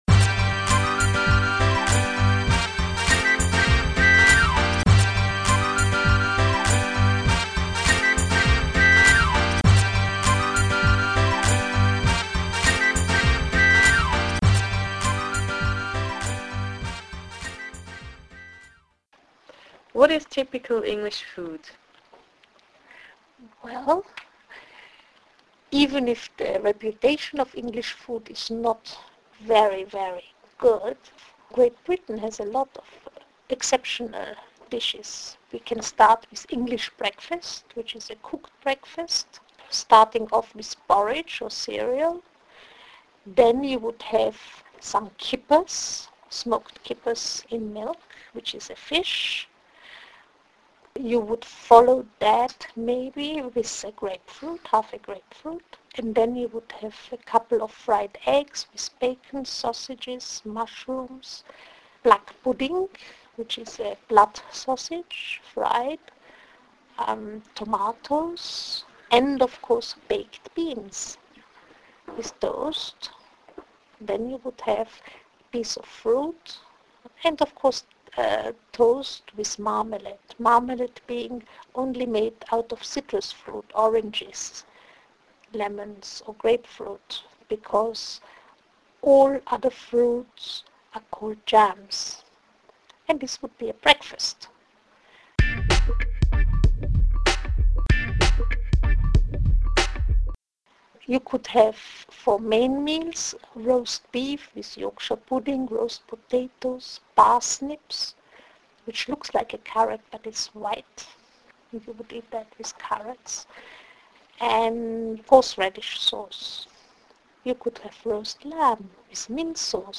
Interview: typical_english_food